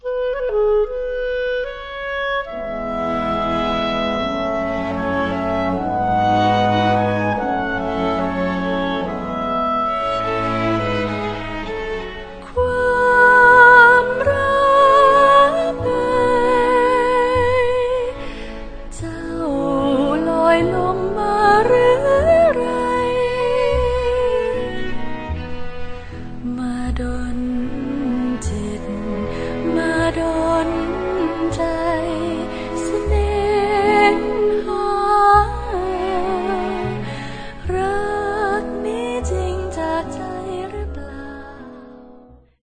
เชลโล
ดับเบิลเบส